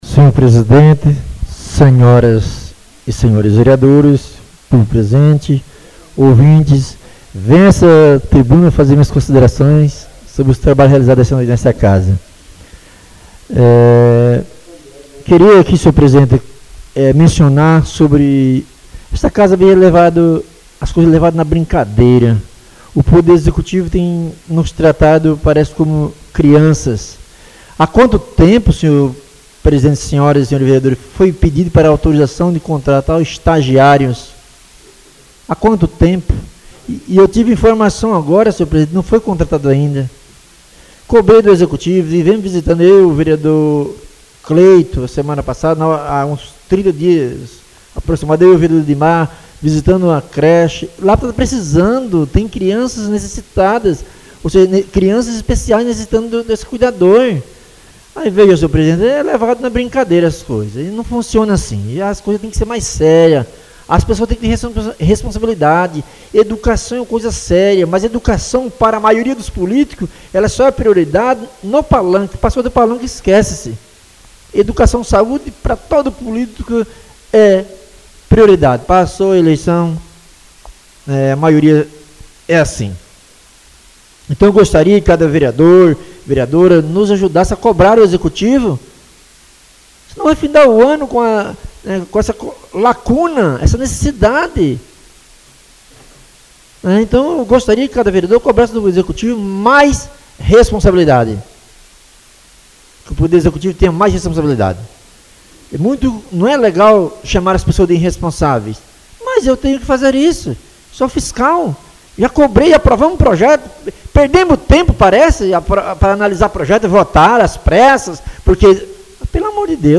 Oradores das Explicações Pessoais (27ª Ordinária da 3ª Sessão Legislativa da 6ª Legislatura)